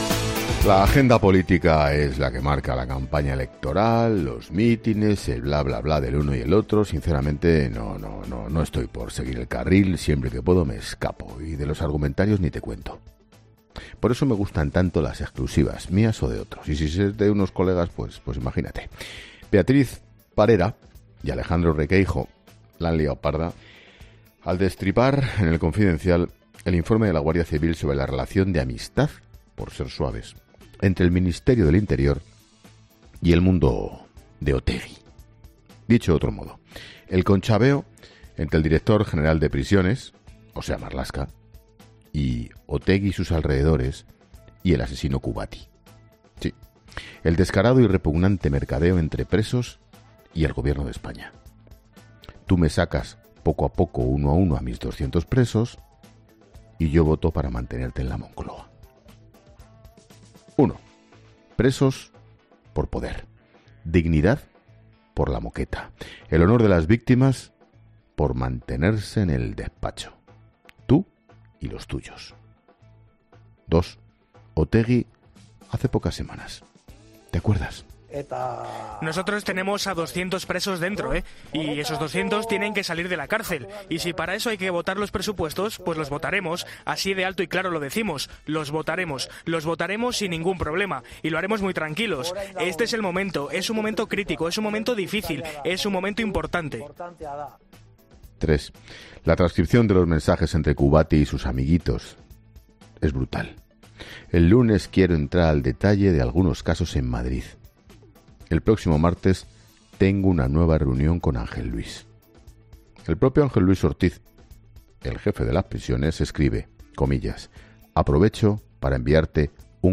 Monólogo de Expósito
El director de 'La Linterna', Ángel Expósito, analiza algunas de las noticias más destacadas de este miércoles 10 de febrero